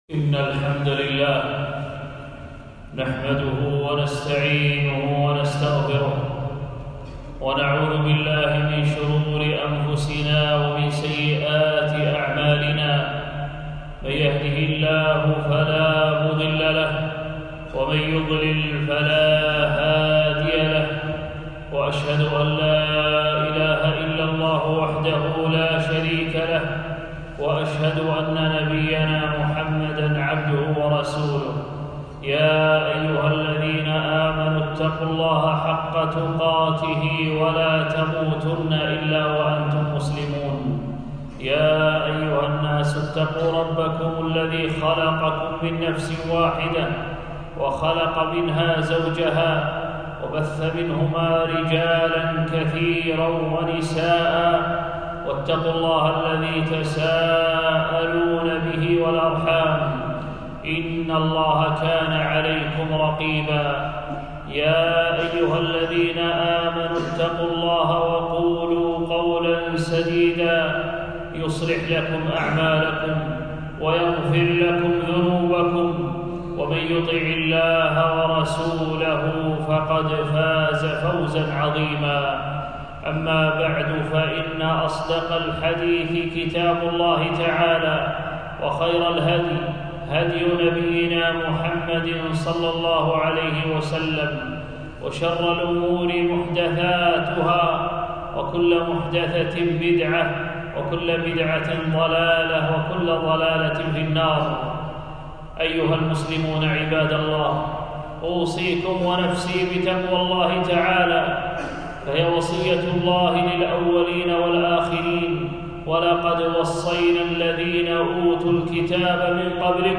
خطبة - آيات الله في الشتاء